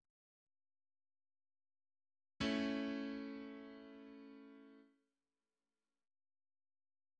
A-dur to również akord, zbudowany z pierwszego (a), trzeciego (cis) i piątego (e) stopnia gamy A-dur.
Akord A-dur